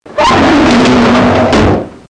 Close Sound Effect
close.mp3